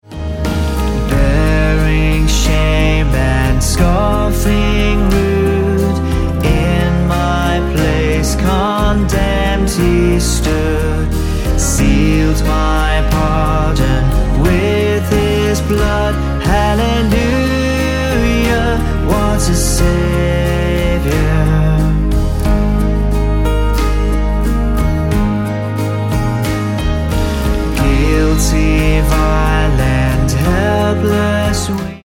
Ab